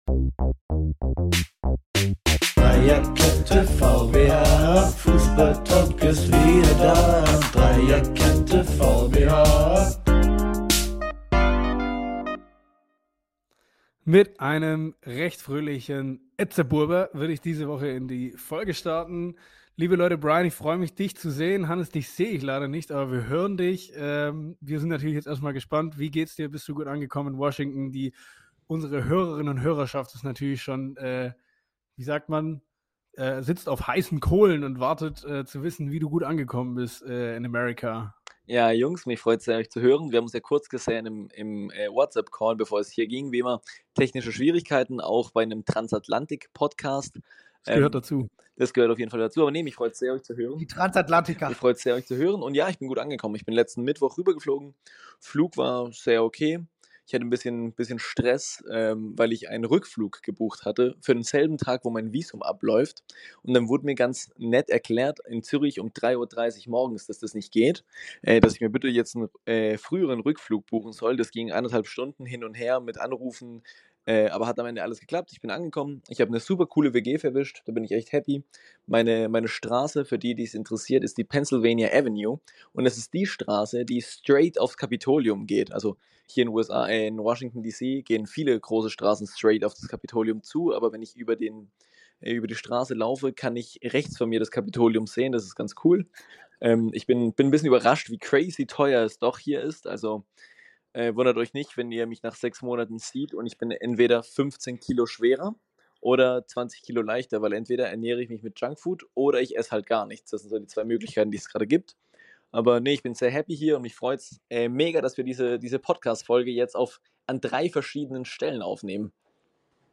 Unsere erste Aufnahme auf zwei Kontinenten verspricht alle Infos zur Thematik Tuchel und das Formtief der Bayern, ein unvergleichbares Quiz und unsere persönliche Meinungen zum Projekt “Wrexham AFC”.